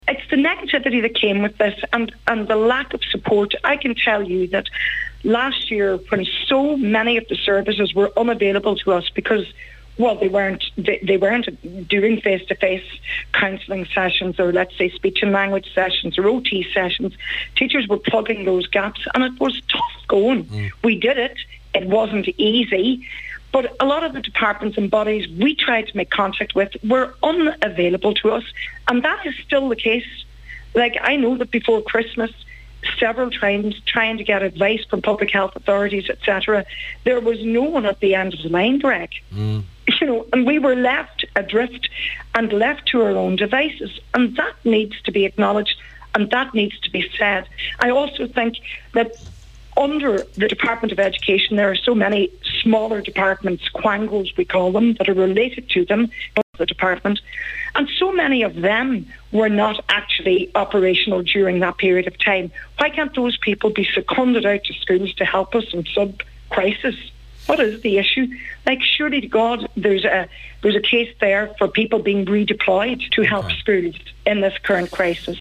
“We were left adrift” – Deputy NS Principal